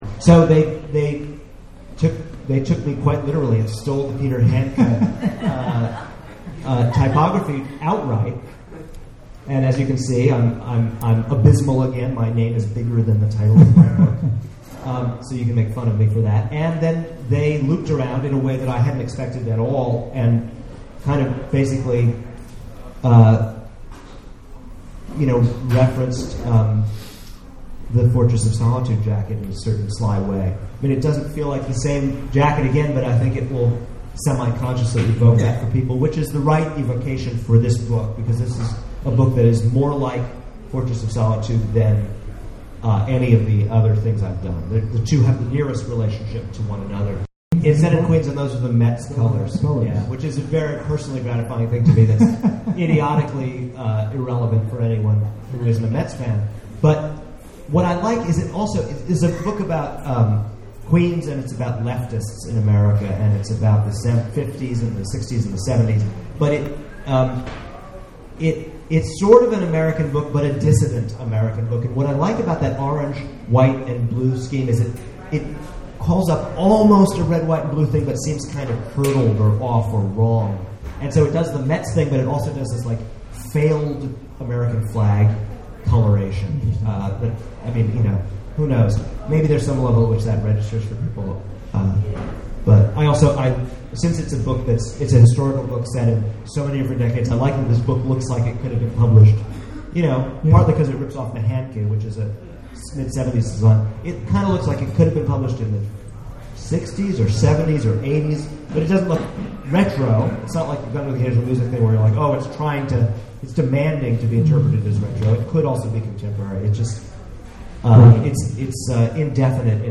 at The Last Bookstore in downtown Los Angeles for an in-depth discussion of his book covers.